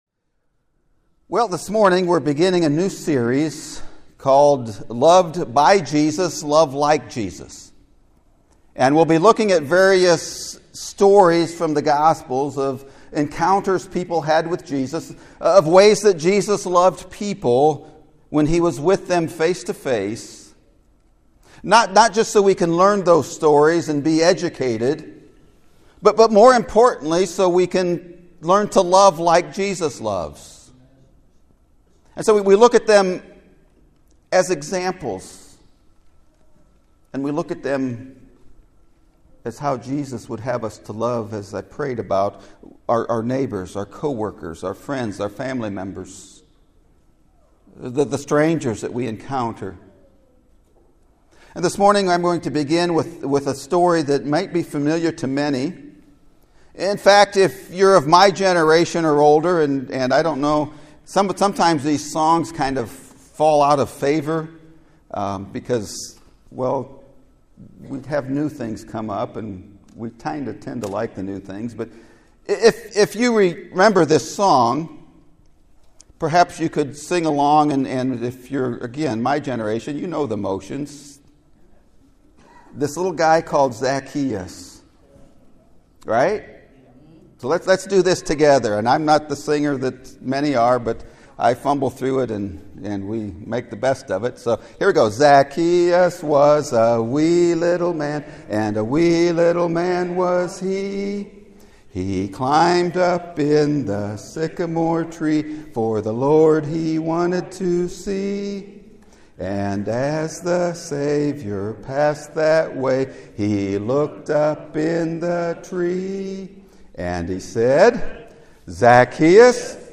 Loving the Overlooked! (Sermon Audio)